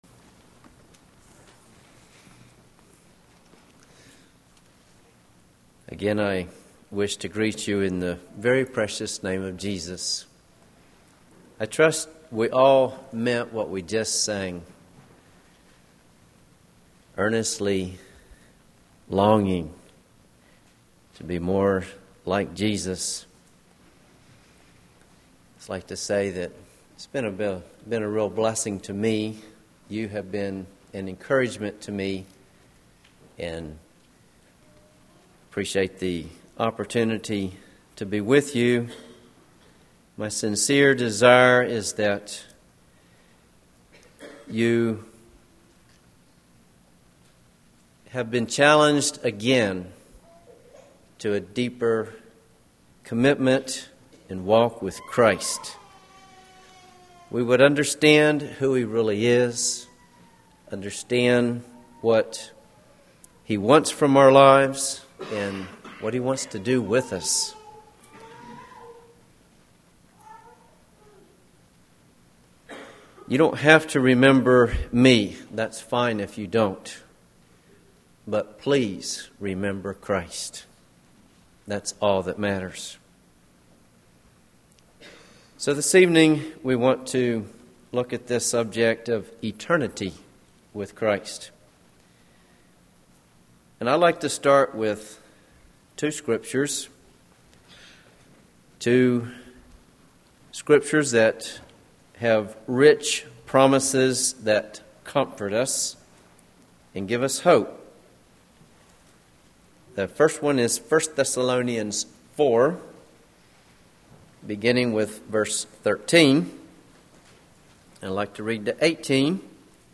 2016 Sermon ID